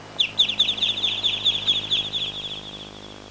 Chirping Ducklings Sound Effect
Download a high-quality chirping ducklings sound effect.
chirping-ducklings.mp3